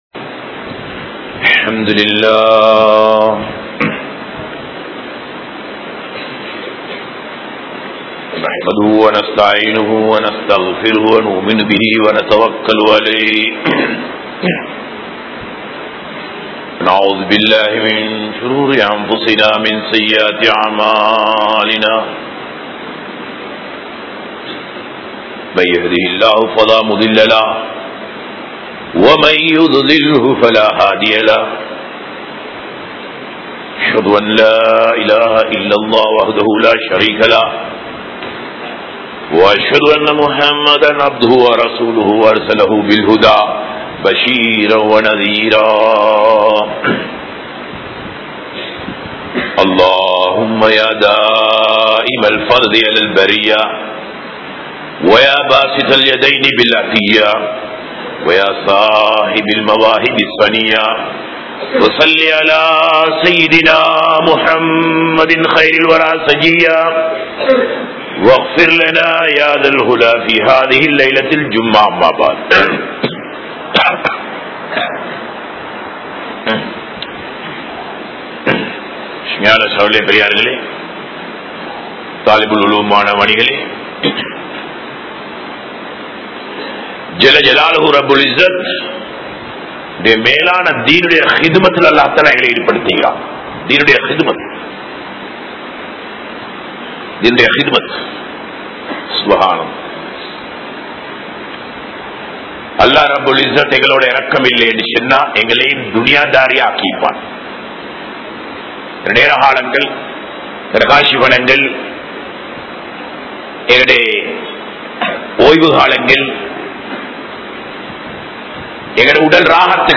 Amalhalil Nadikkum Manitharhal (அமல்களில் நடிக்கும் மனிதர்கள்) | Audio Bayans | All Ceylon Muslim Youth Community | Addalaichenai
Meera Sahib Jumua Masjith